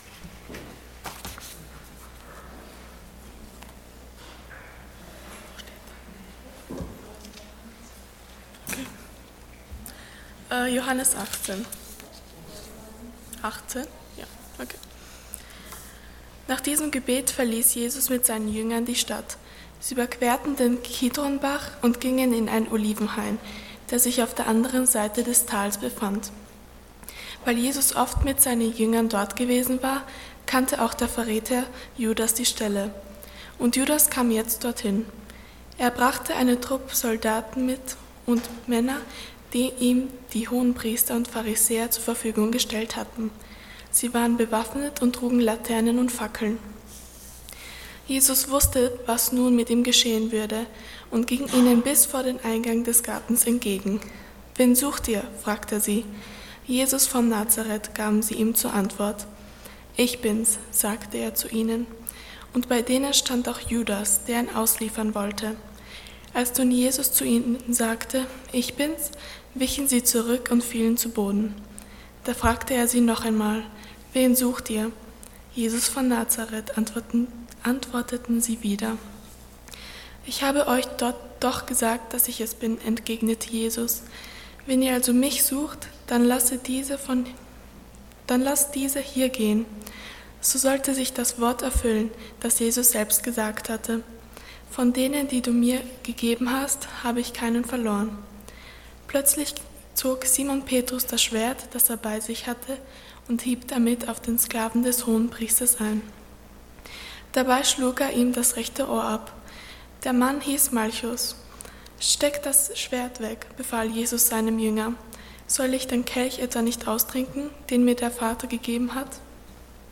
Passage: John 18:1-11 Dienstart: Sonntag Morgen